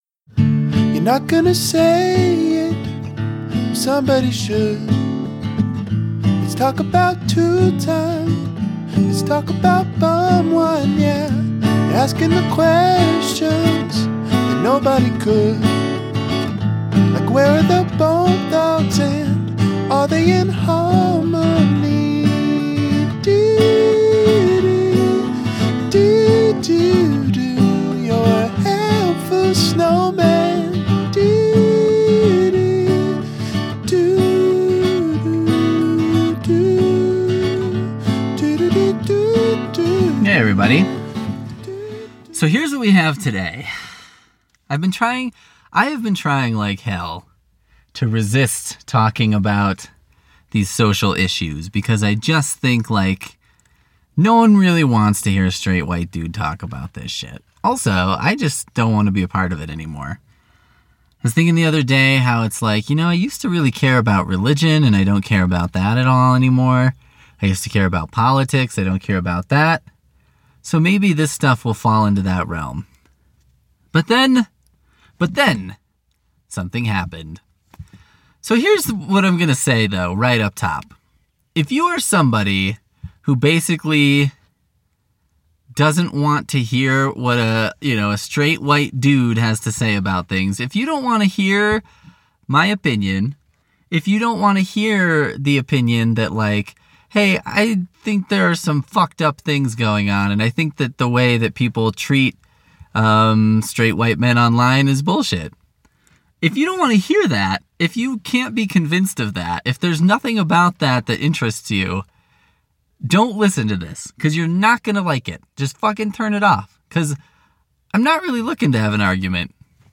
Just me yelling about Buzzfeed.